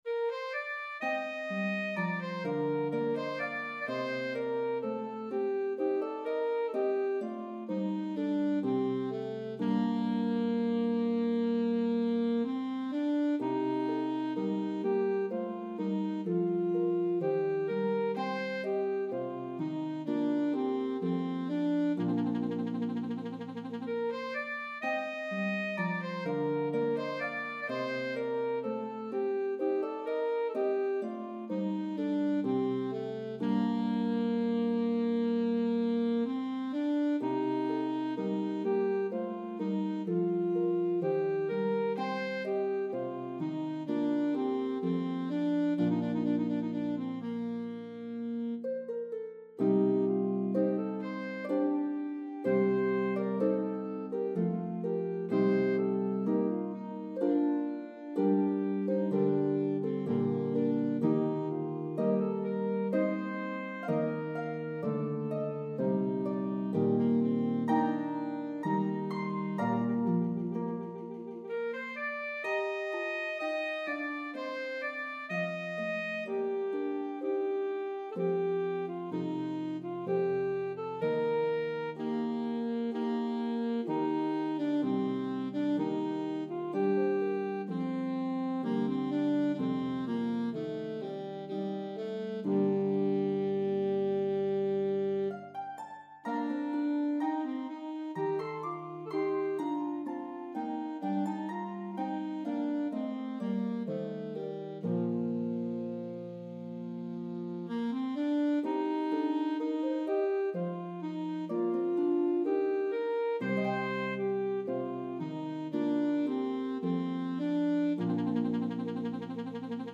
Italian Baroque style
slow air